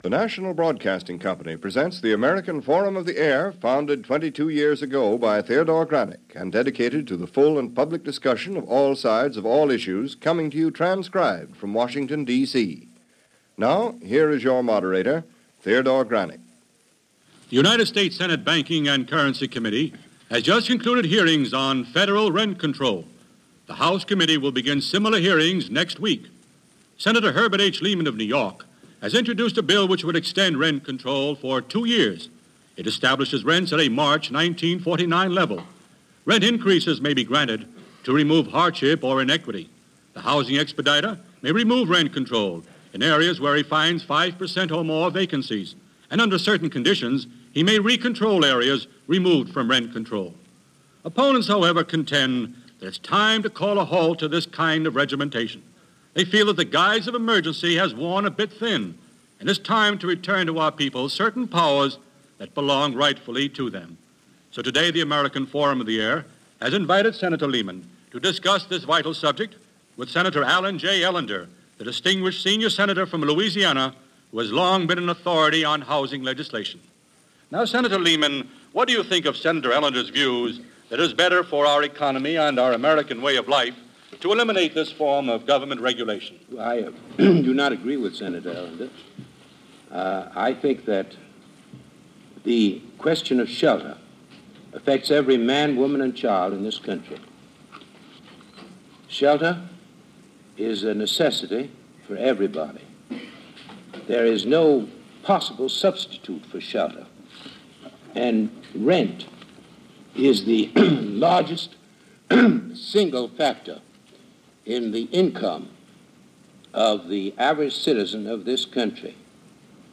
This episode of American Forum Of The Air debates the Rent Control question as it pertained to the period during World War 2 when defense plants and mass hirings created a surge of housing shortages throughout the country, particularly in urban areas. The debate was over whether national Rent Controls should be relaxed or stay in place.